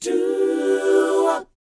DOWOP A GD.wav